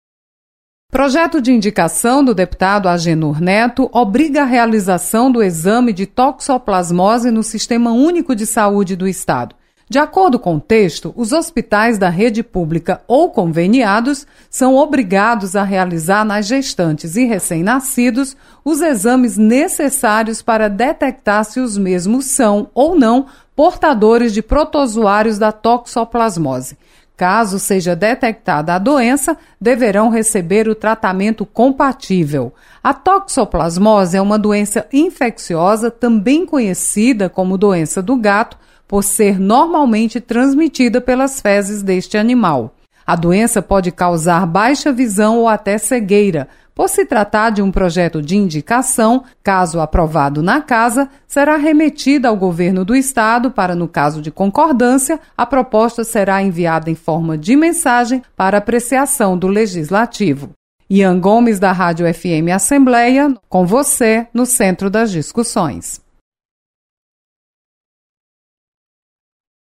Deputado quer medidas de prevenção à doença transmitida por gatos. Repórter